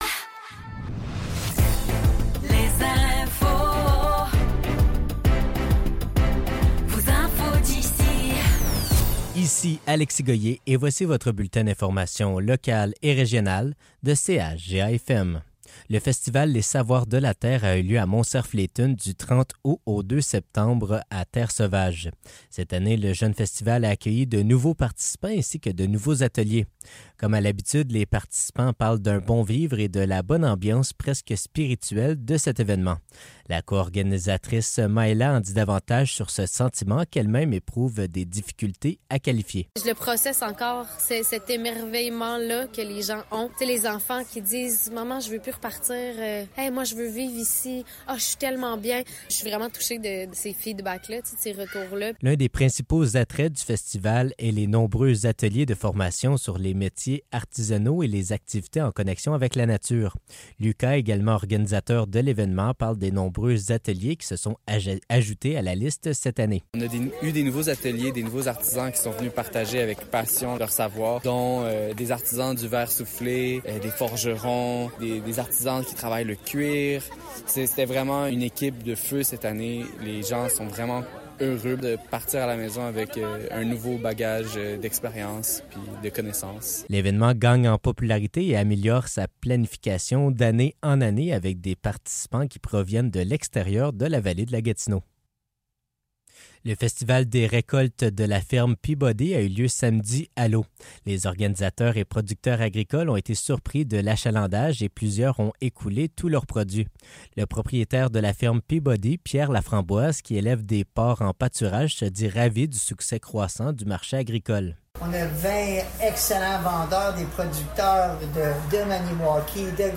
Nouvelles locales - 3 septembre 2024 - 10 h